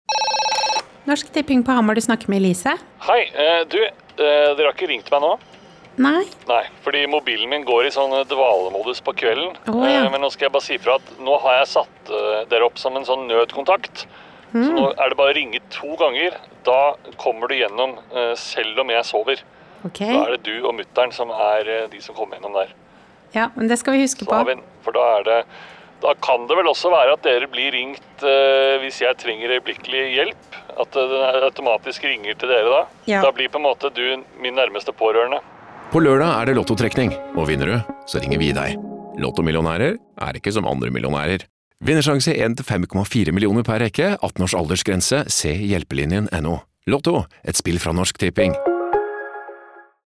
Regi må trekkes frem her og spesielt karakteren Elise er en favoritt: den stadige balanseringen mellom høflig og irritert er nydelig utført.